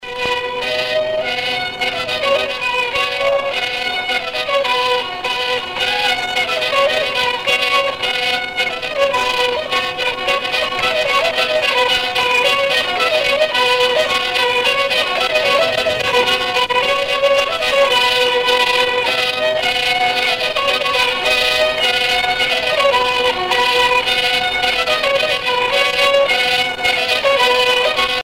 danse : polka piquée
Pièce musicale éditée